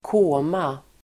Uttal: [²k'å:ma]